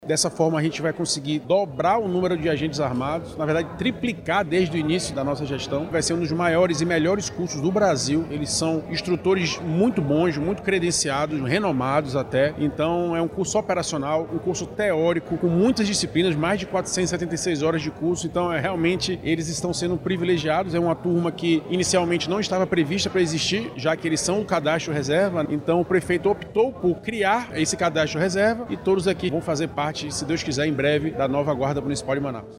O secretário municipal de Segurança Pública, Alberto de Siqueira, explicou que o curso é intensivo e operacional.